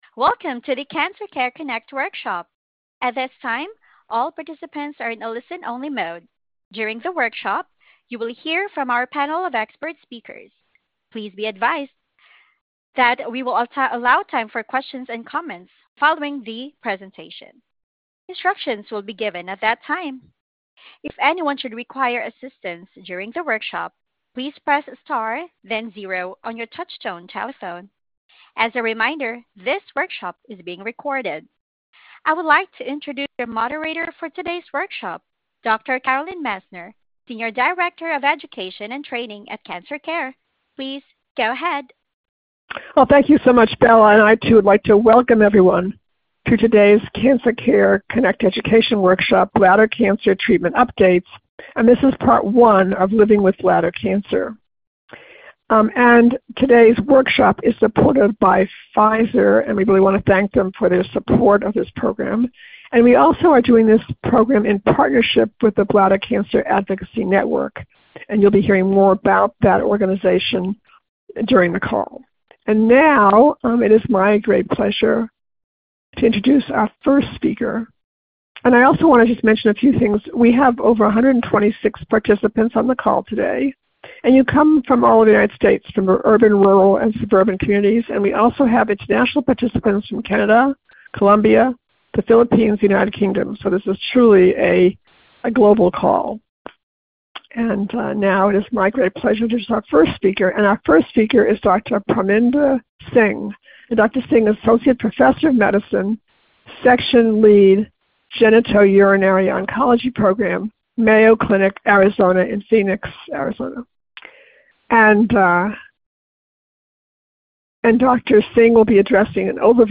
Questions for Our Panel of Experts
This workshop was originally recorded on August 20, 2025.